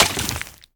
assets / minecraft / sounds / mob / bogged / hurt1.ogg
hurt1.ogg